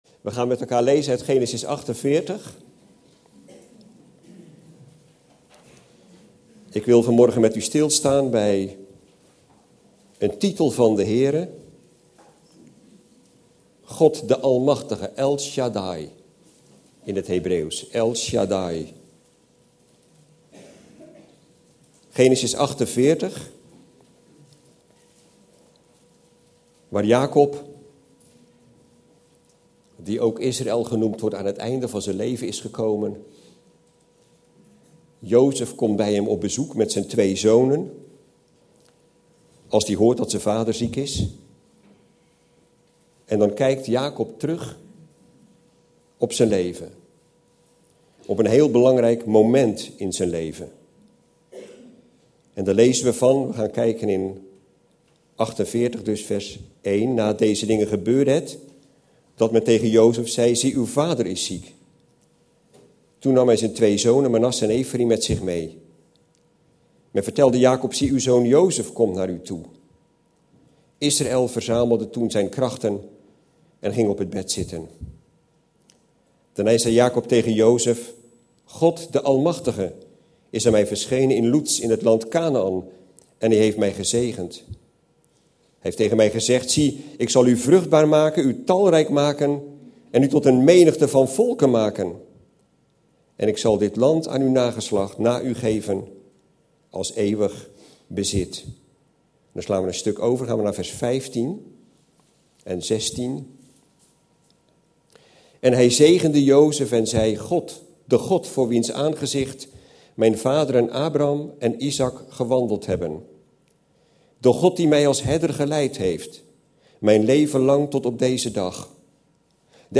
In de preek aangehaalde bijbelteksten (Statenvertaling)Genesis 48:1-161 Het geschiedde nu na deze dingen, dat men Jozef zeide: Zie, uw vader is krank!